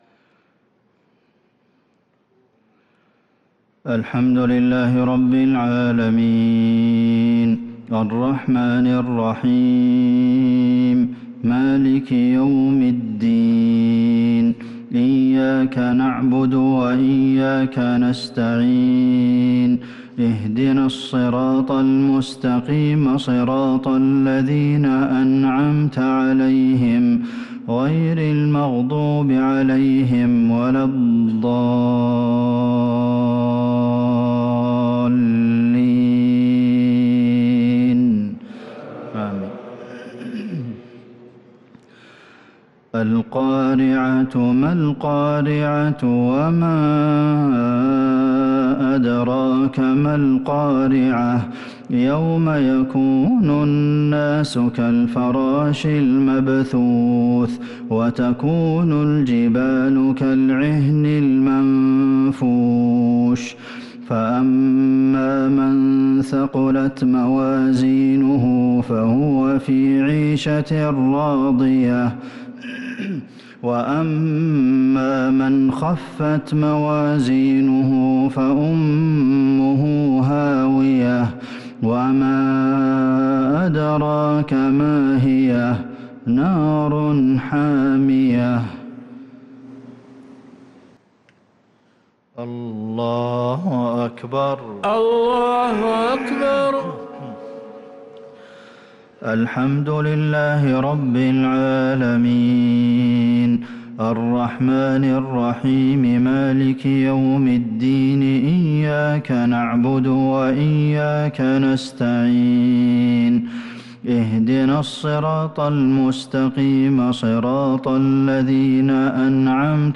صلاة المغرب للقارئ عبدالمحسن القاسم 22 شوال 1443 هـ